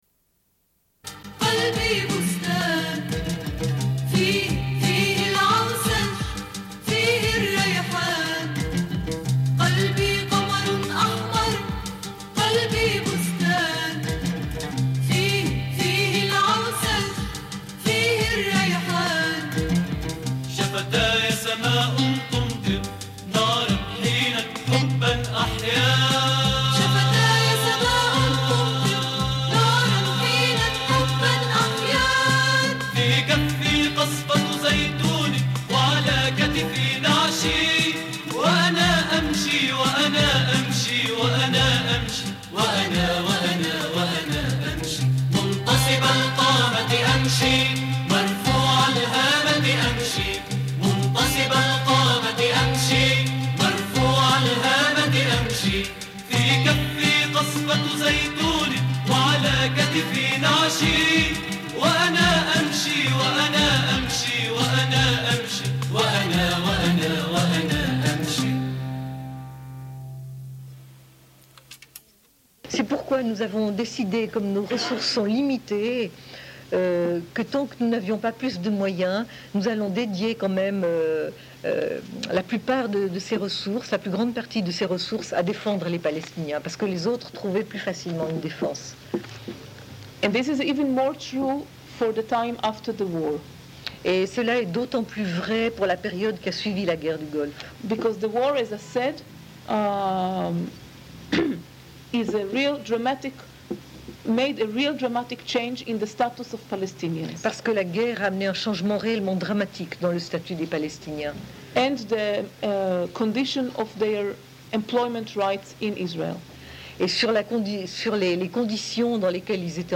Une cassette audio, face B00:28:49
Suite de l'émission : rediffusion d'une conférence donnée par Amira Hass, militante syndicaliste israélienne, invitée par l'Association pour l'Union des peuples juifs et palestiniens, l'Union des syndicats du Canton de Genève et par le syndicat SIT. Elle parle du Workers' Hotline, groupe israélo-palestinien pour la défense des droits des travailleurs.